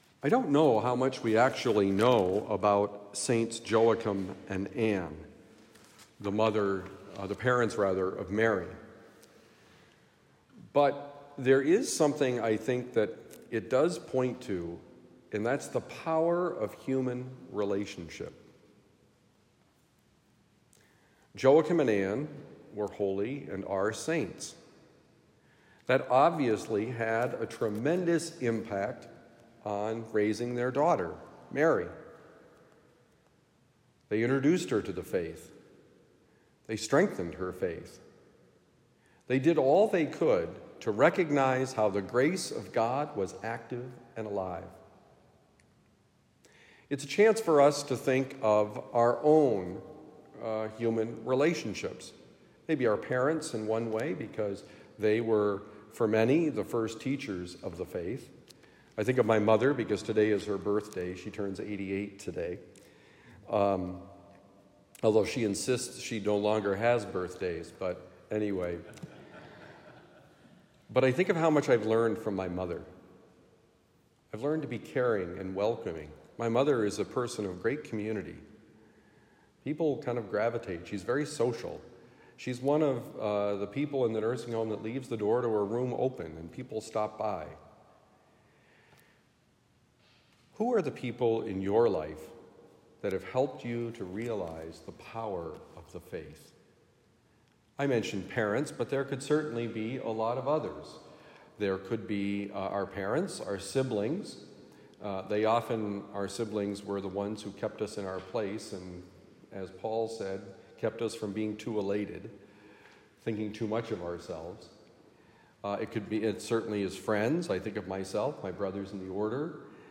The power of human relationship: Homily for Friday, July 26, 2024